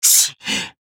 坂田金时_受击2.wav